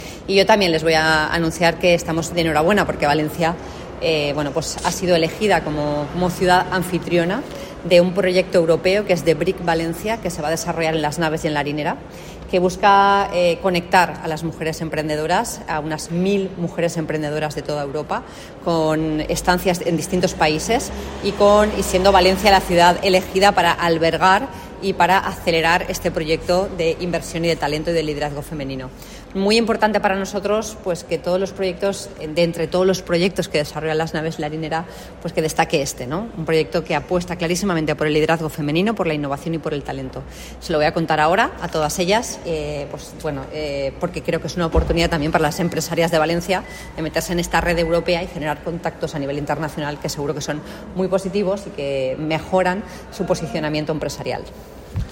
• La alcaldesa, durante el encuentro